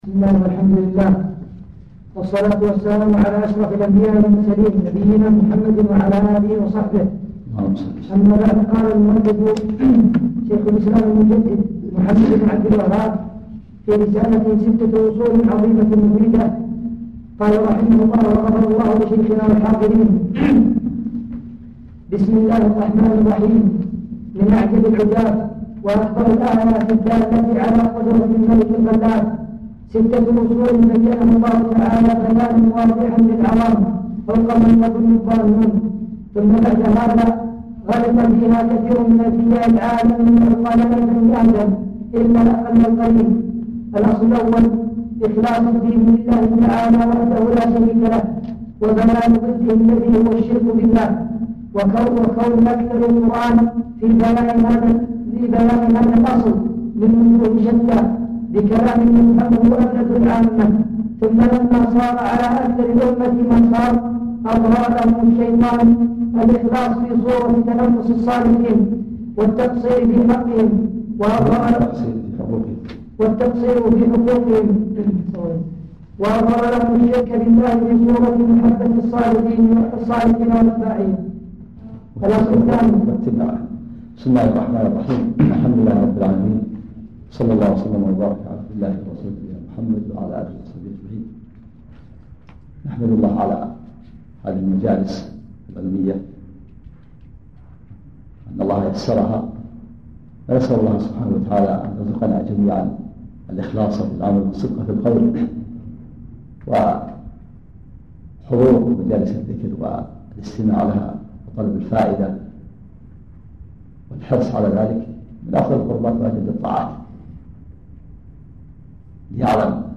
محاضرة صوتية نافعة، وفيها شرح الشيخ عبد العزيز الراجحي